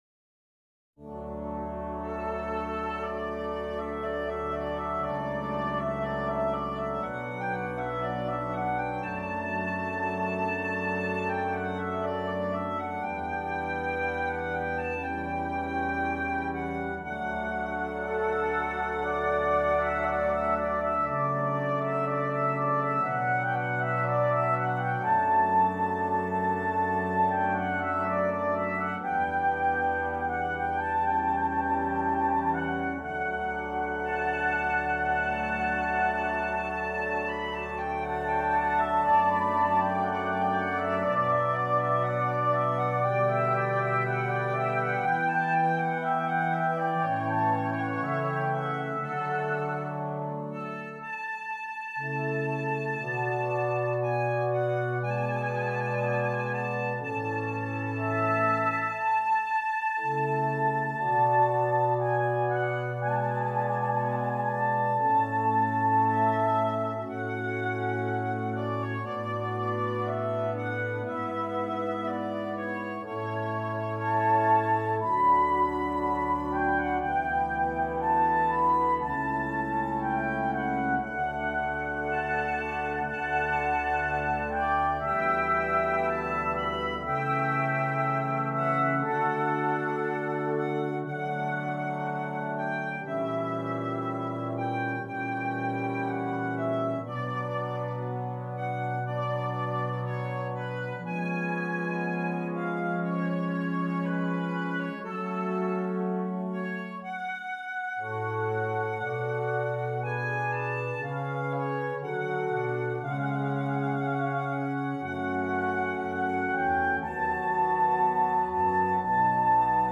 Brass Quintet and Soprano